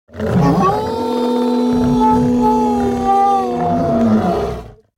جلوه های صوتی
دانلود صدای گرگ 9 از ساعد نیوز با لینک مستقیم و کیفیت بالا